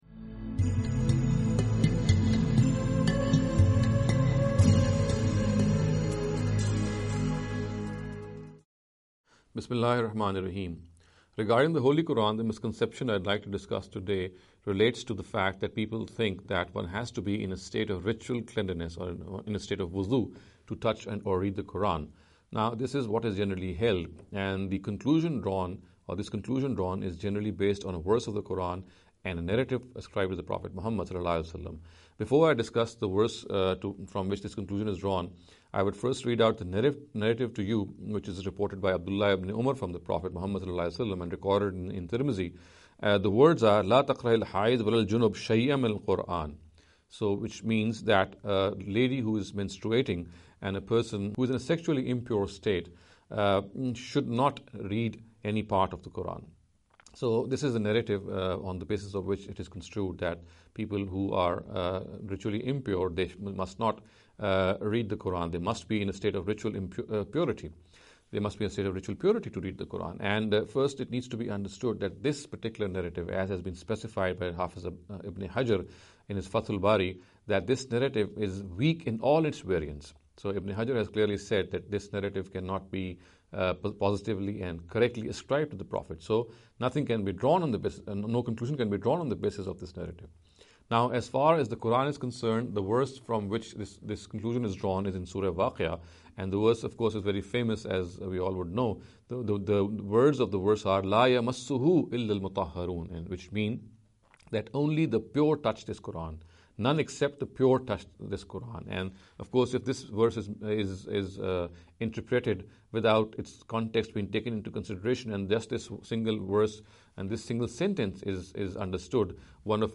This lecture series will deal with some misconception regarding the Holy Qur’an.